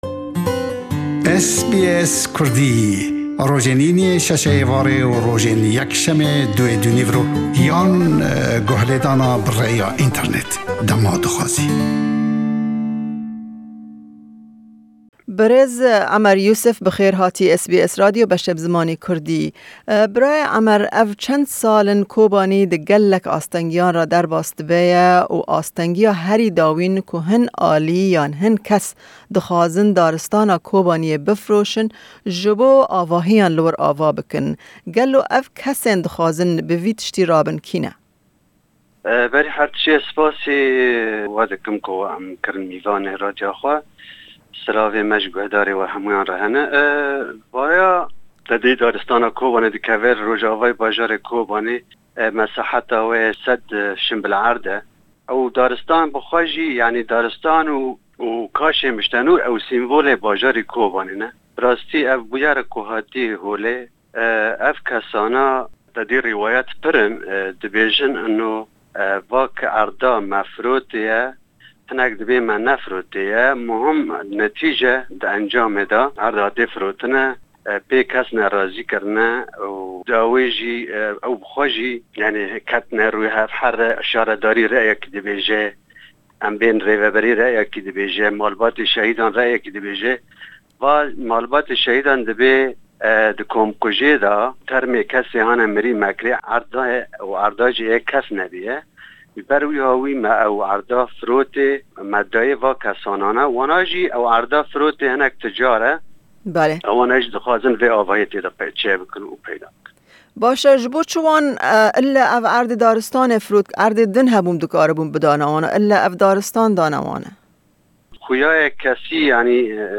Me derbarî projeyeke avakirina avahiyan li daristana Kobaniyê hevpeyvînek